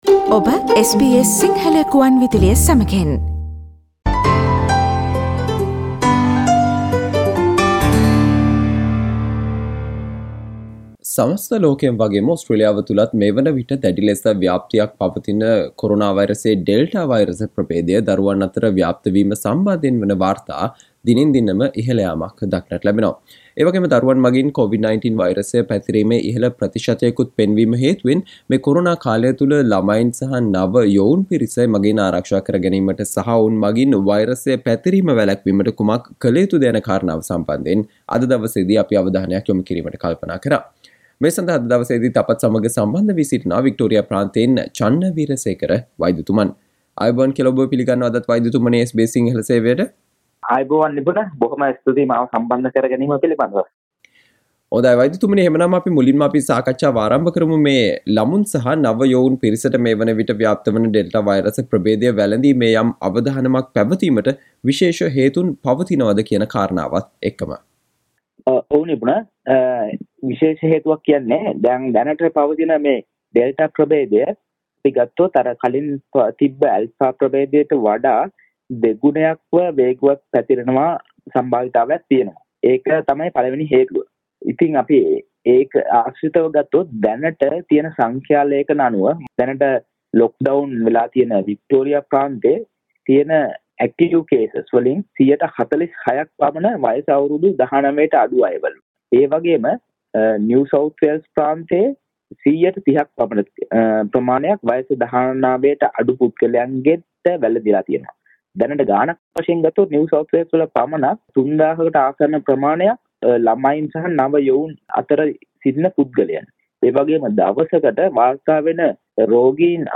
Listen to the SBS Sinhala discussion on What can be done to keep Children and teens away being a spreaders and protect them during COVID-19